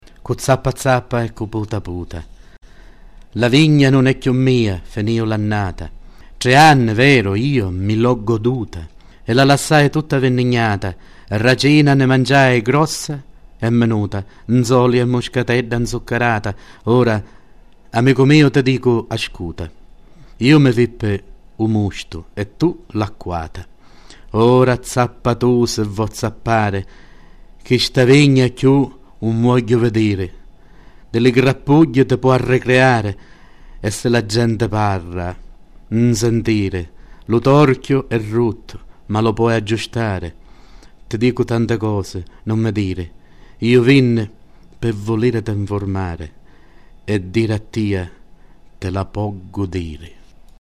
I documenti presenti in questo archivio sono in formato mp3 e sono stati digitalizzati e restaurati recentemente da supporti audio tradizionali. La qualità di alcuni, comunque, non è ottimale.